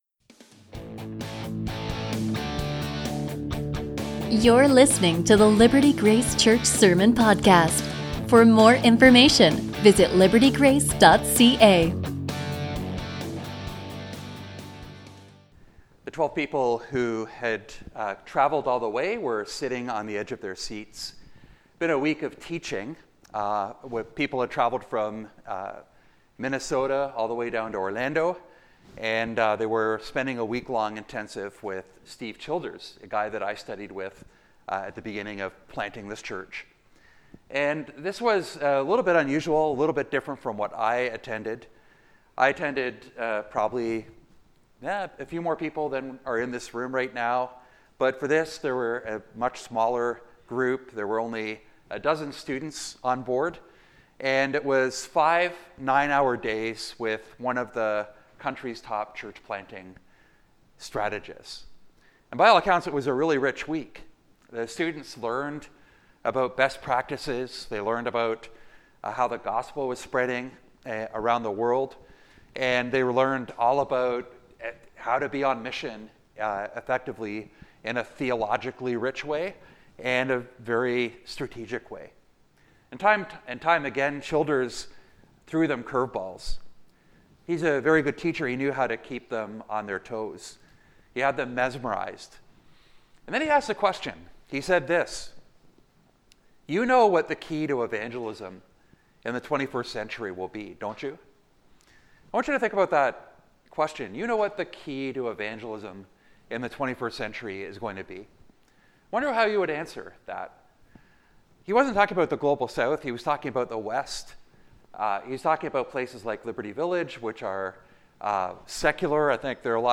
Sermons Luke Message